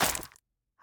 Minecraft Version Minecraft Version 1.21.5 Latest Release | Latest Snapshot 1.21.5 / assets / minecraft / sounds / block / roots / break1.ogg Compare With Compare With Latest Release | Latest Snapshot
break1.ogg